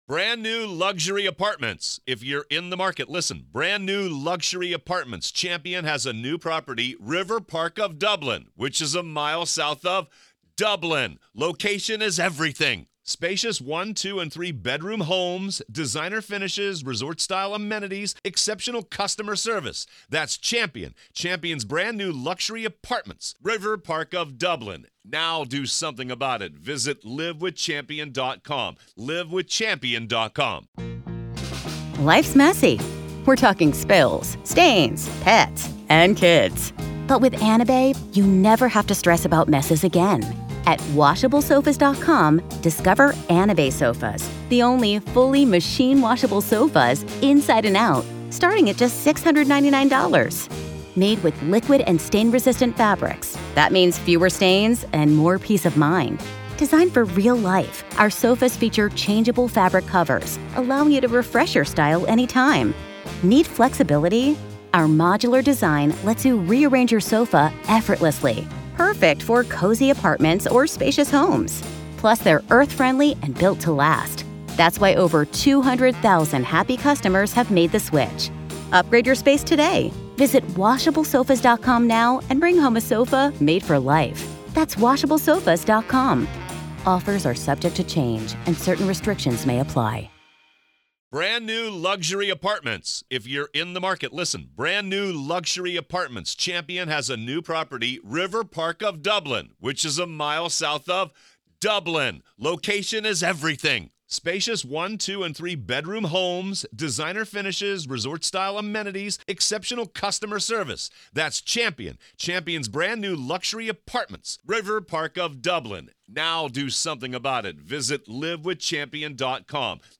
Alex Murdaugh Trial: Courtroom Coverage | Day 6, Part 6